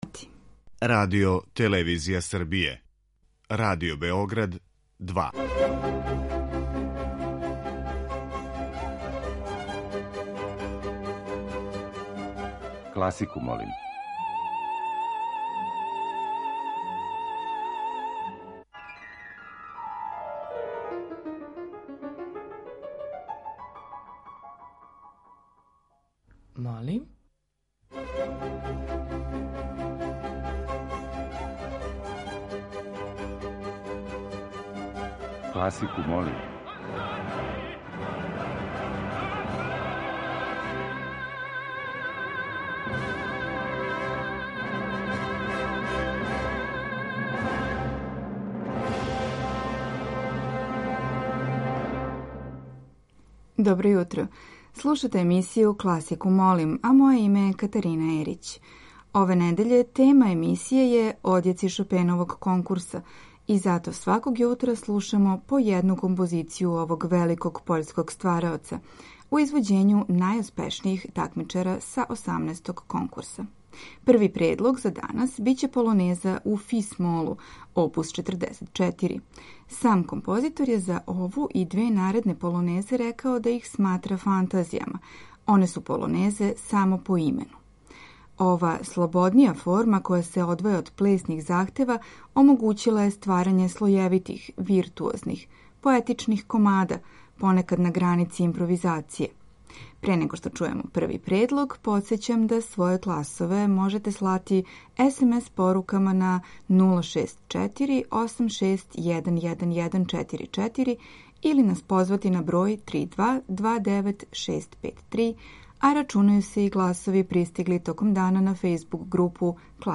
Снимци најбољих такмичара на последњем Шопеновом конкурсу у Варшави.